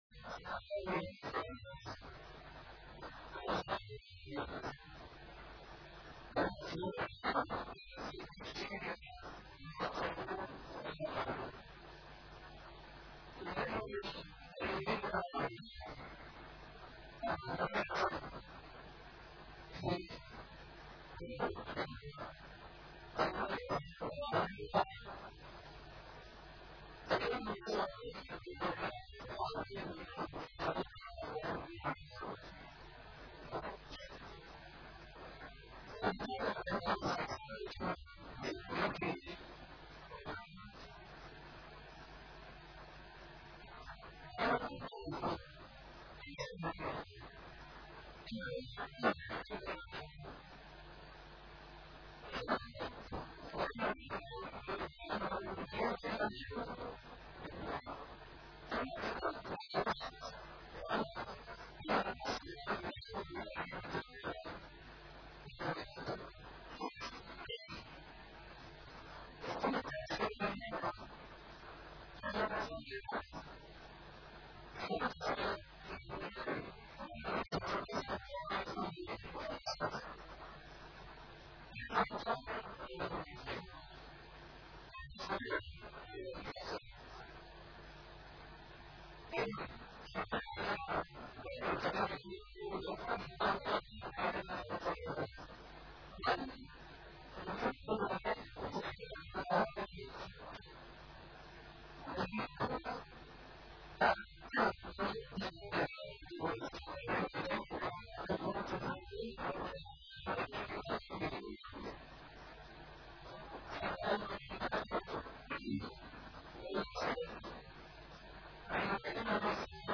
محاضرات متفرقة في مناسبات مختلفة - A MARTYR SCHOLAR: IMAM MUHAMMAD SAEED RAMADAN AL-BOUTI - الدروس العلمية - عندما تتعارض قوميتي مع ديني